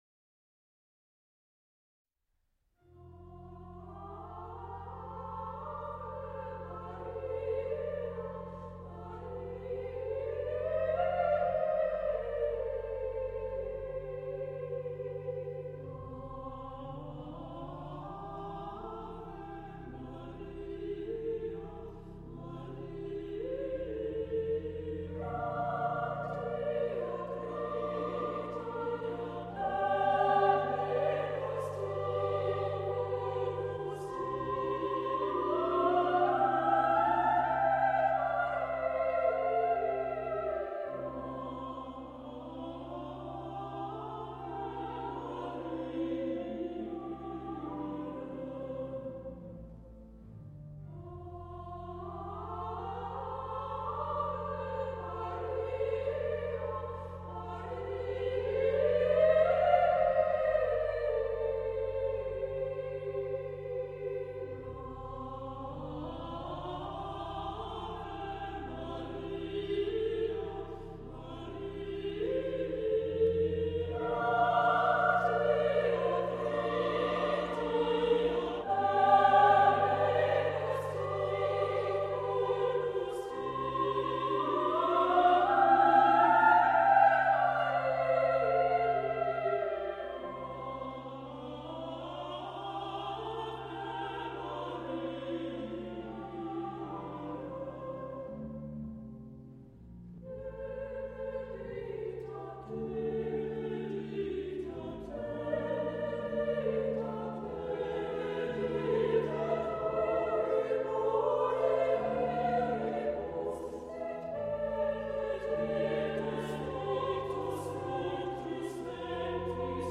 Choir
A musical ensemble of singers.
for female chorus and organ